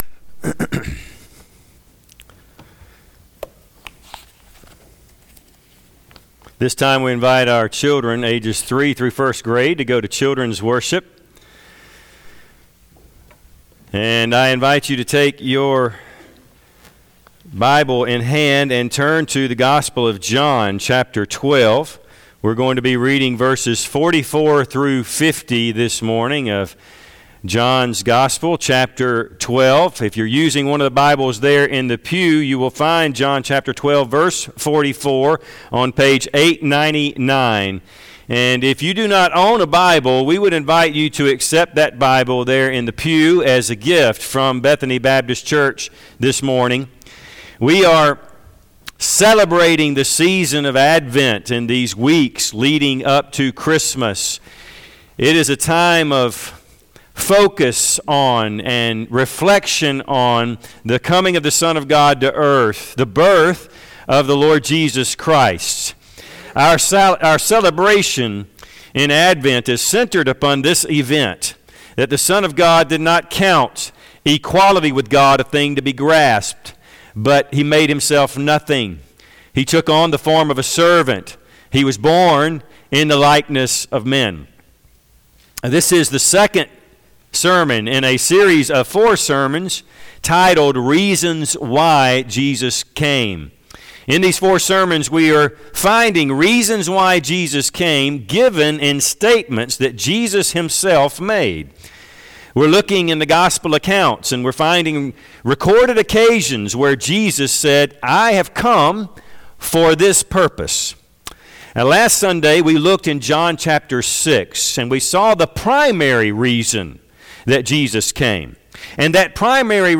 Passage: John 12:44-50 Service Type: Sunday AM